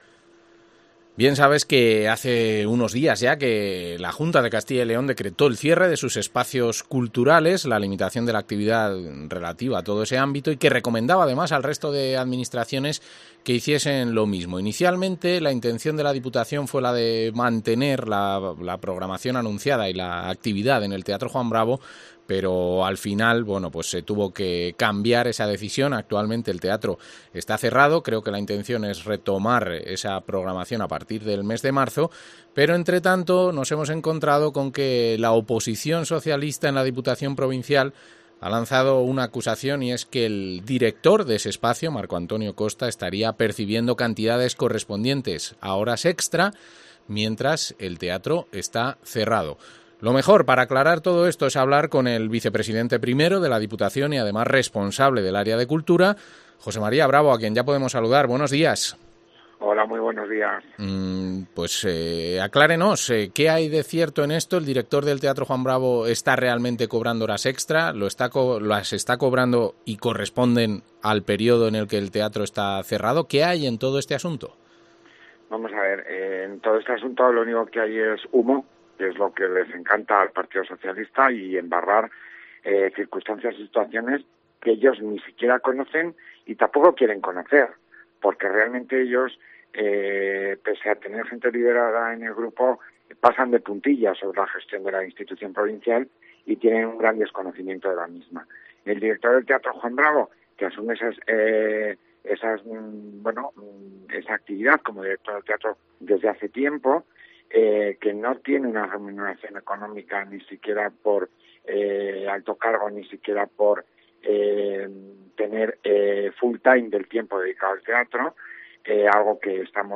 Entrevista al diputaco de Cultura, José María Bravo